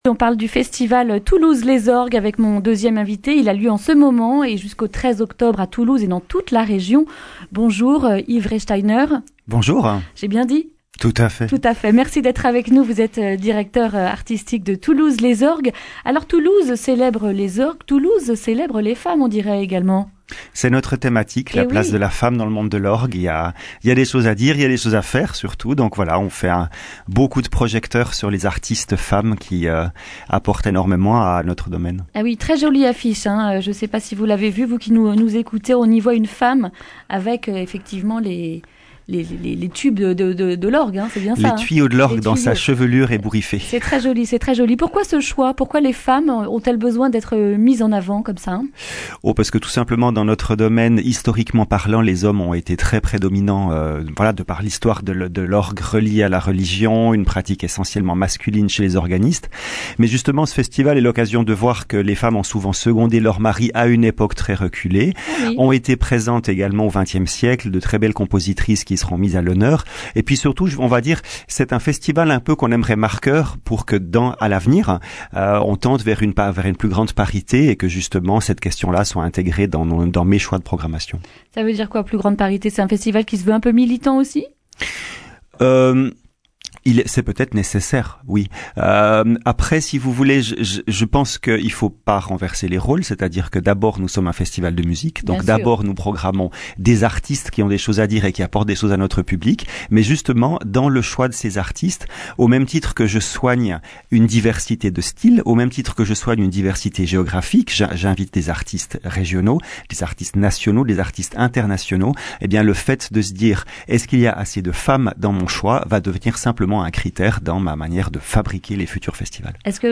mercredi 2 octobre 2019 Le grand entretien Durée 11 min
Une émission présentée par